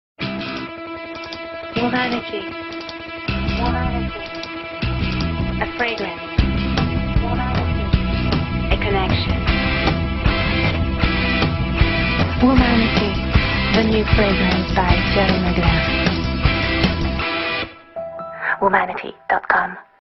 Voix Off Français, Anglais, Italien. Voix jeune, dynamique, posée, sexy, claire.
Sprechprobe: Werbung (Muttersprache):